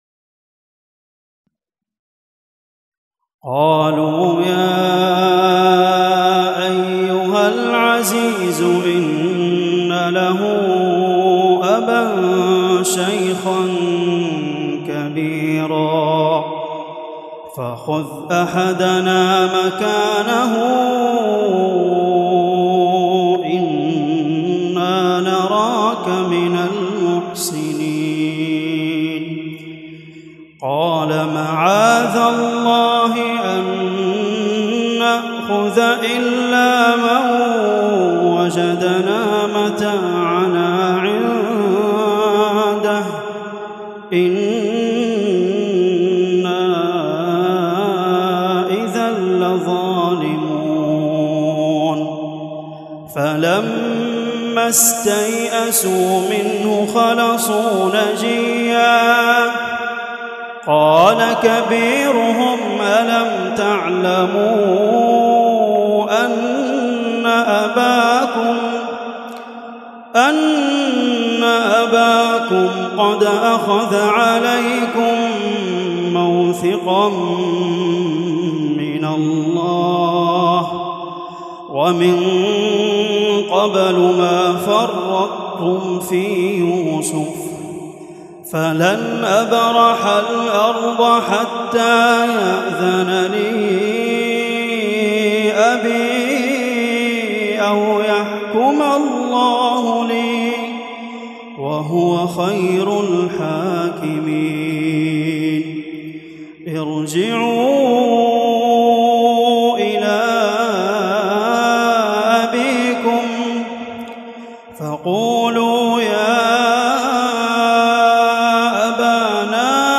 تلاوه شجيه ترقق القلوب